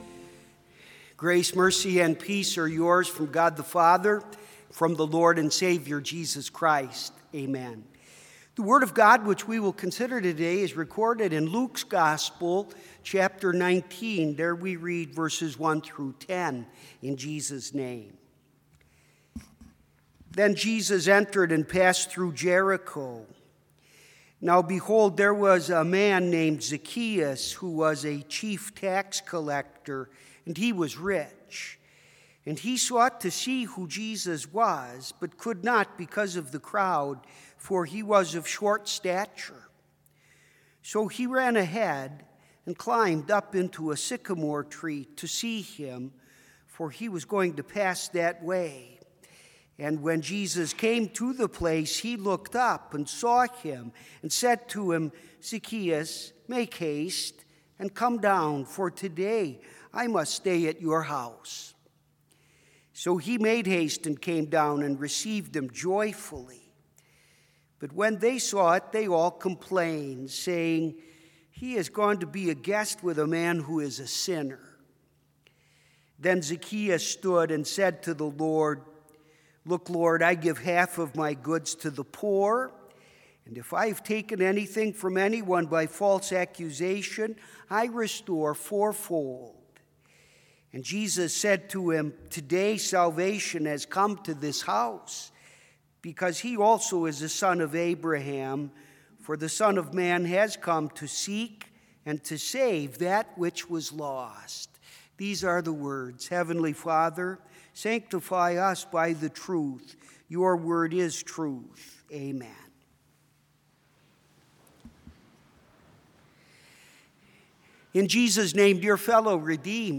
Complete service audio for Chapel - February 11, 2020